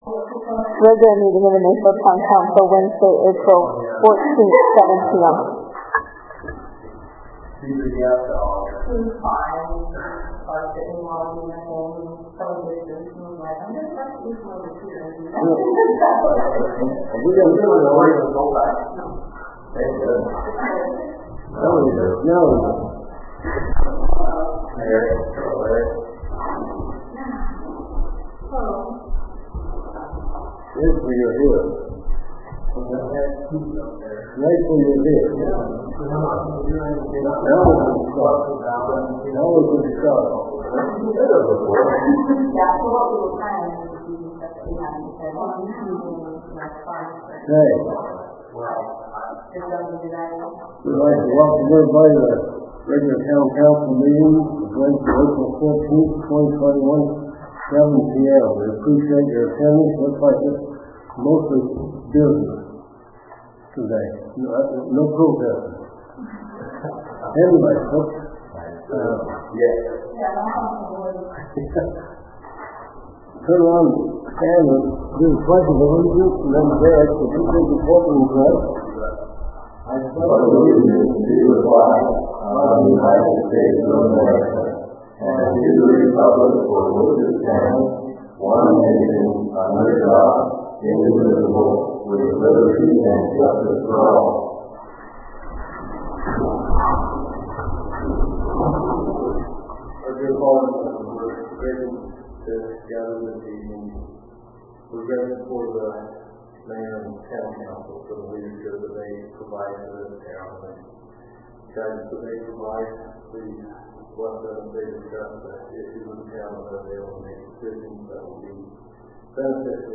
Regular Town Council Meeting
Pursuant to Utah Code Ann. §§ 52-4-207 et. seq., Open and Public Meetings Act some of the Town Council and public may appear electronically.